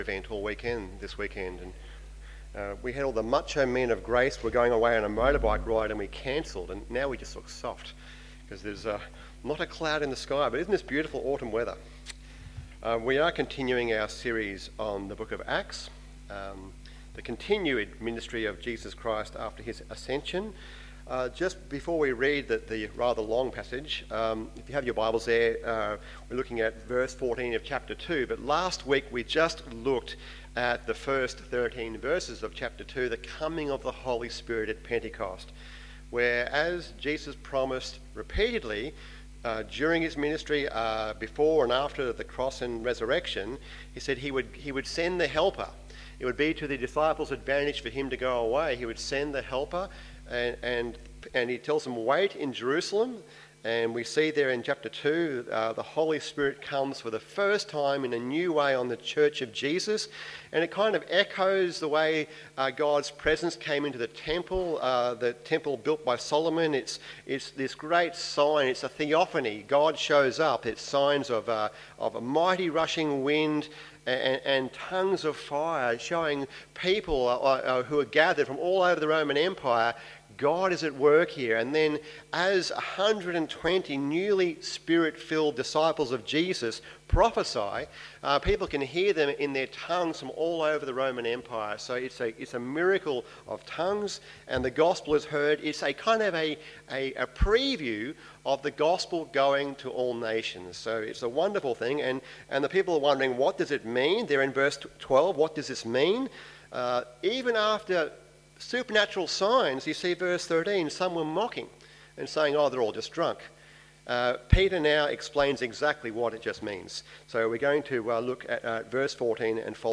It’s All About Jesus AM Service